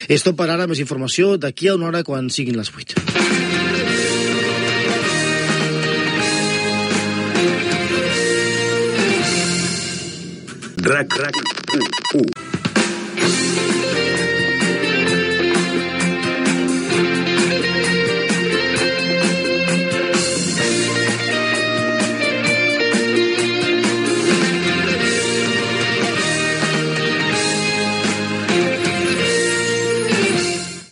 Final de les notícies, indicatiu i sintonia de l'emissora